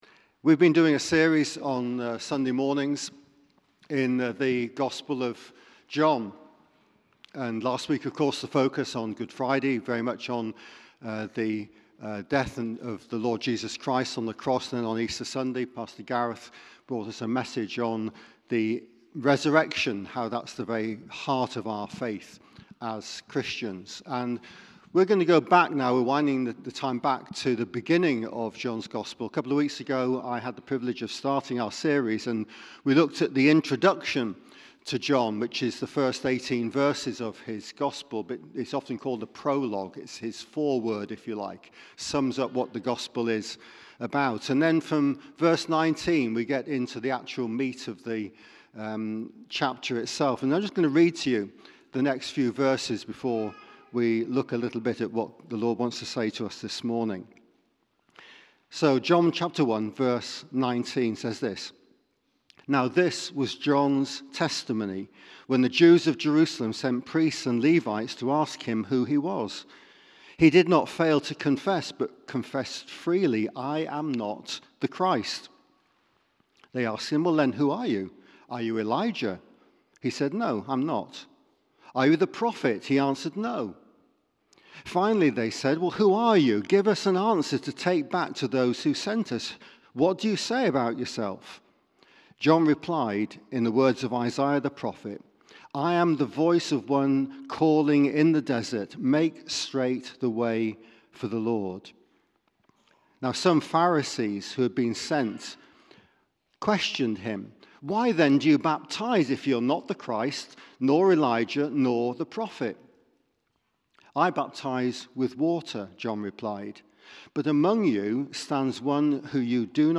Sunday 16th April 2023 Sermon